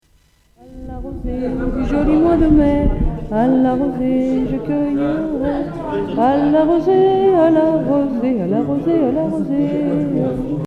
Origine : Lyonnais Année de l'arrangement : 2012
Chant : une jeune femme , à Fourneaux dans la nuit du 1er mai 1992.